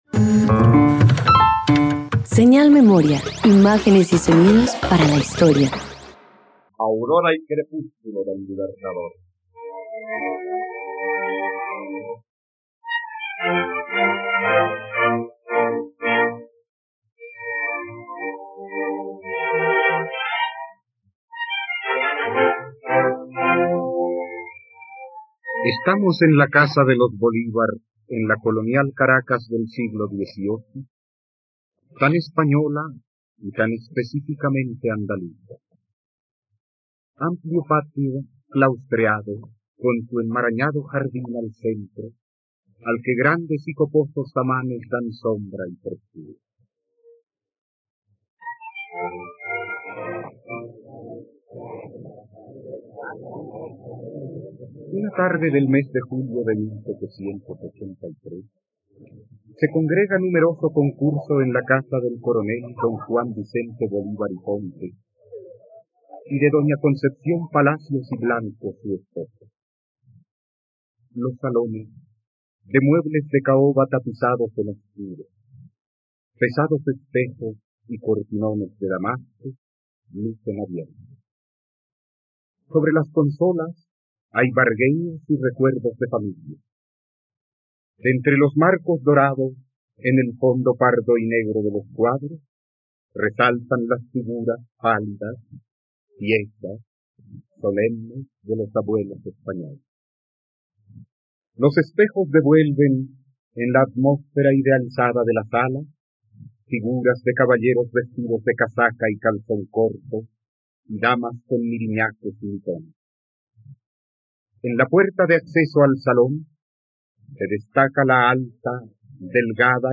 Aurora y crepúsculo del Libertador - Radioteatro dominical | RTVCPlay